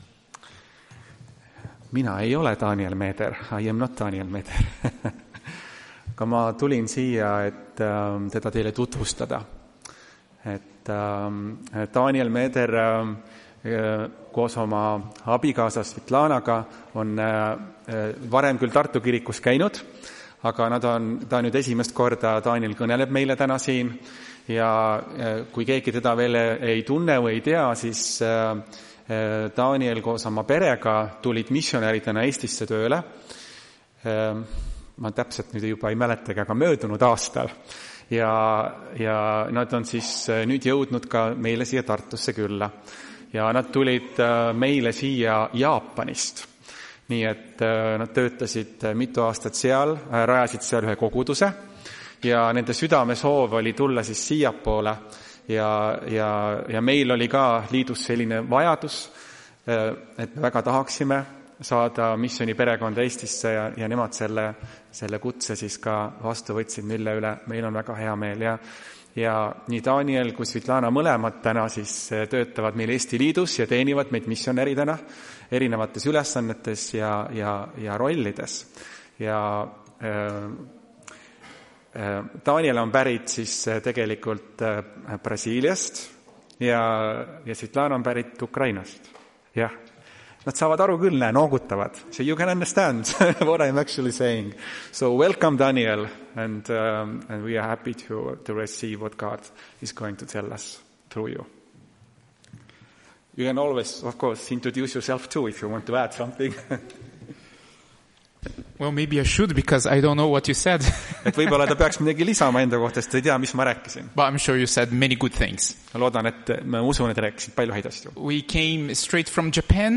Tartu adventkoguduse 16.08.2025 teenistuse jutluse helisalvestis.